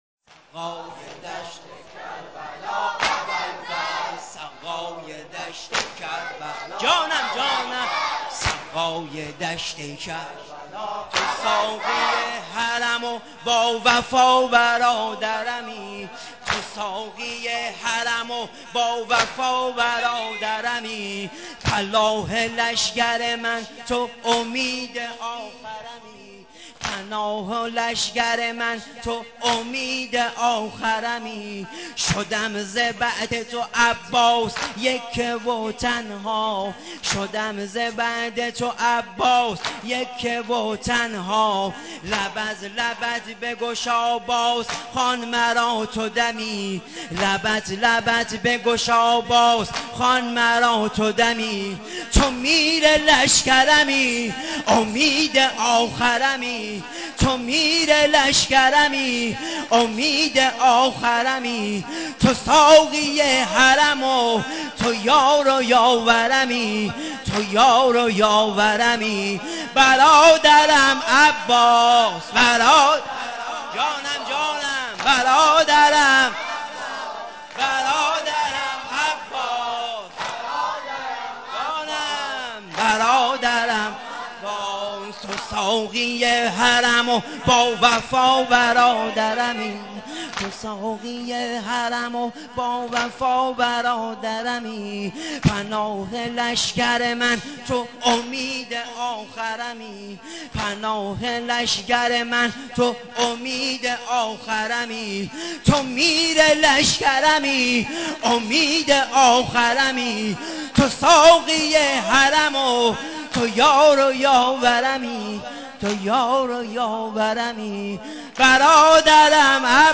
ذاکر اهل بیت
نوحه سینه زنی ساقی حرم محرم 97